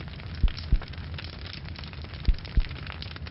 wicker_fire.wav